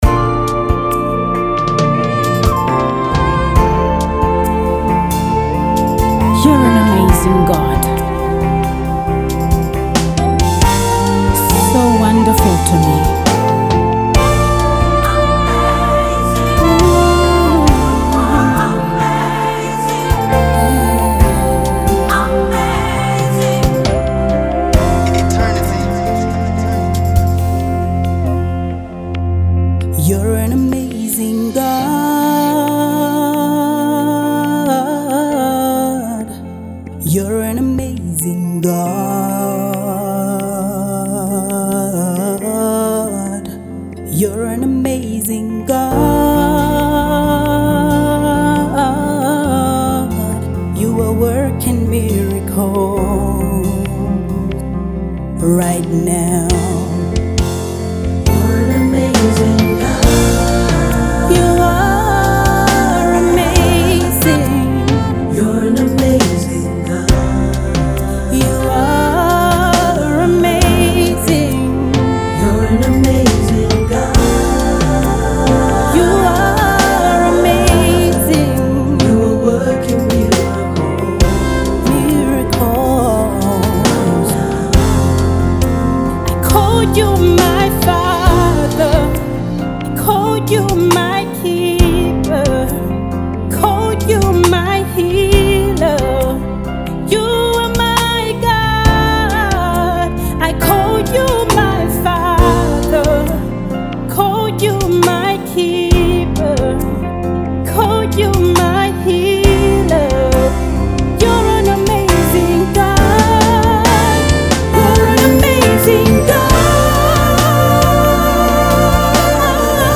an uplifting worship song which oozes peace and comfort